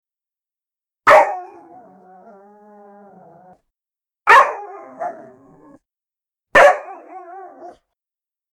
Dog Barking Sfx Sound Effect Download: Instant Soundboard Button
Dog Barking Sound1,260 views